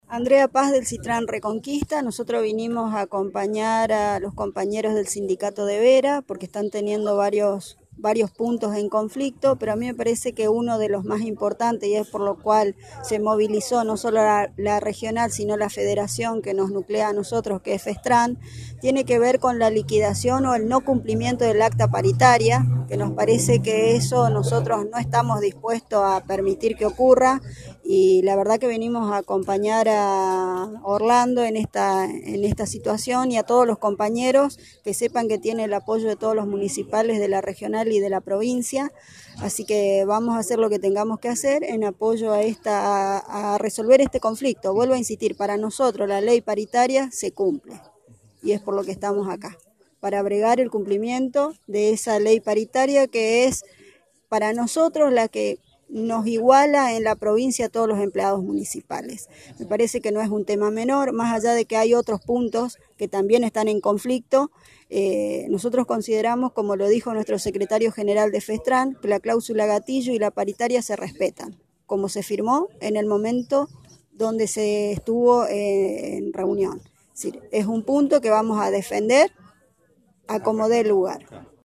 En contacto con Radio EME Vera, referentes de la marcha expresaron los motivos de la misma.